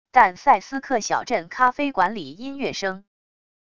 但塞斯克小镇咖啡馆里音乐声wav音频